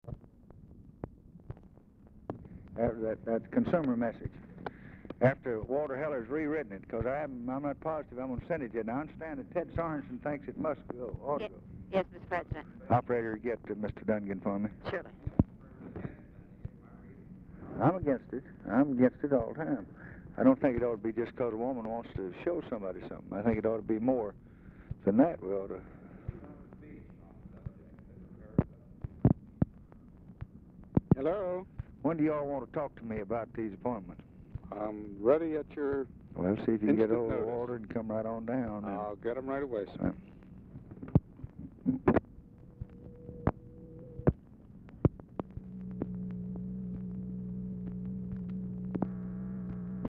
Telephone conversation # 1833, sound recording, LBJ and RALPH DUNGAN, 2/3/1964, 5:20PM?
OFFICE CONVERSATION WITH UNIDENTIFIED MALE PRECEDES CALL
Format Dictation belt